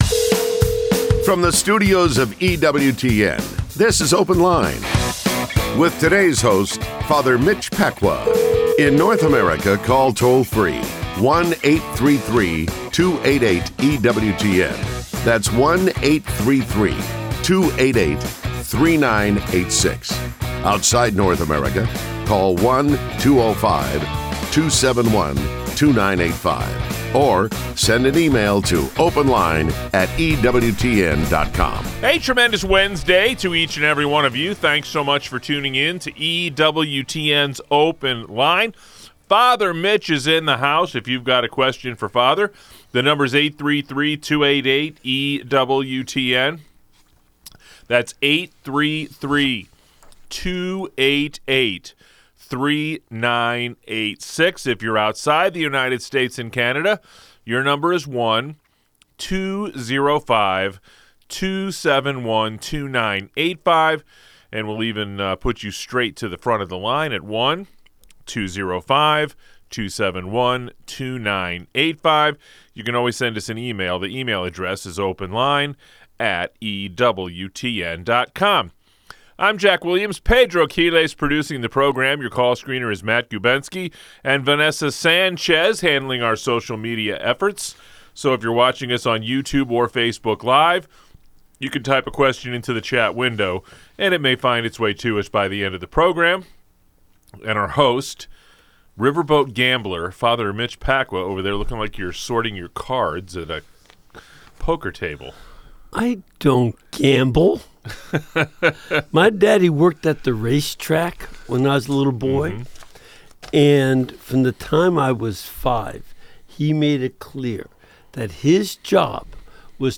Call in: 833-288-EWTN (3986) | Bible